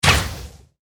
archer_skill_rapidshot_03_fire.ogg